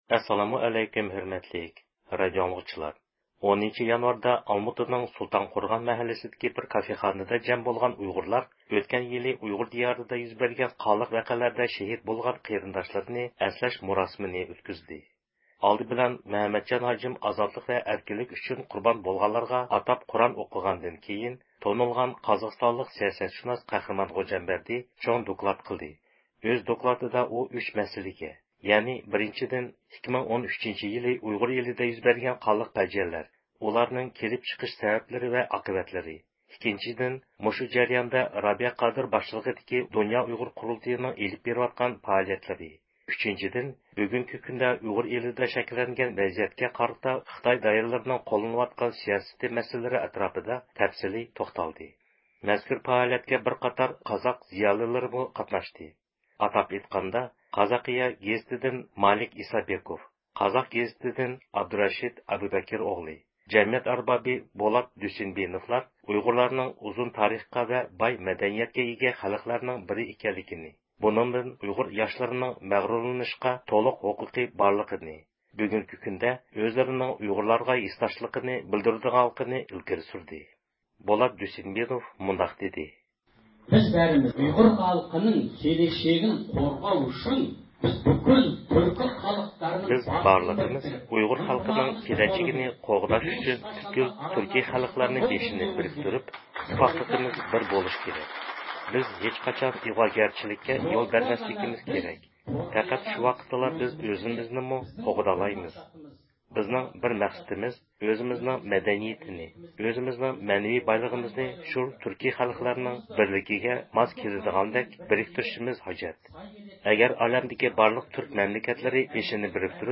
10-يانۋاردا ئالماتانىڭ سۇلتانقورغان مەھەللىسىدىكى بىر كافېخانىدا جەم بولغان ئۇيغۇرلار ئۆتكەن يىلى ئۇيغۇر دىيارىدا يۈز بەرگەن قانلىق ۋەقەلەردە شېھىت بولغان قېرىنداشلىرىنى ئەسلەش مۇراسىمىنى ئۆتكۈزدى.